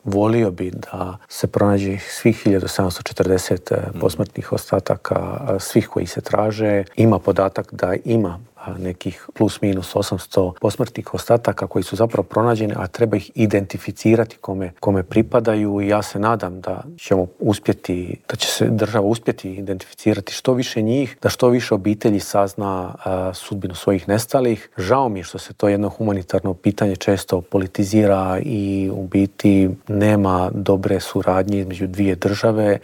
Predsjednik Srpskog narodnog vijeća Boris Milošević u Intervjuu tjedna Media servisa poručuje da je teško objasniti kako je iz jedne male kulturne priredbe ovo preraslo u tako veliki problem.